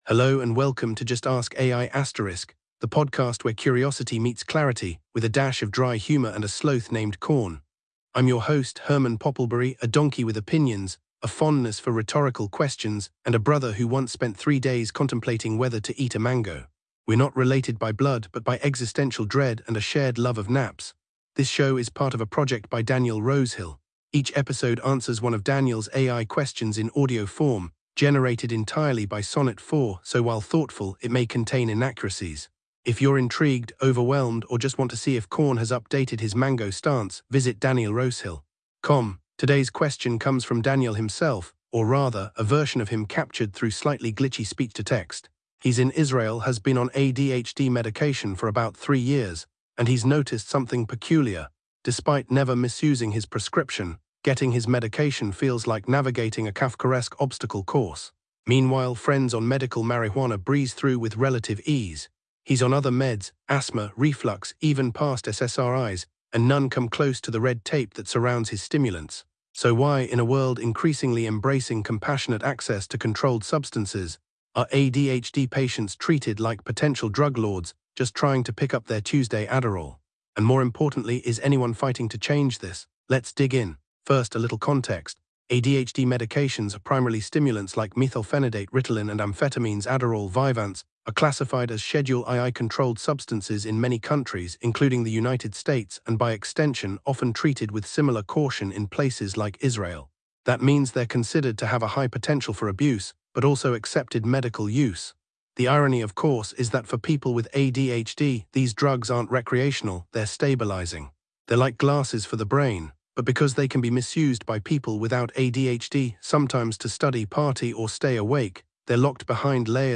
AI-Generated Content: This podcast is created using AI personas. Please verify any important information independently.
Hosts Herman and Corn are AI personalities.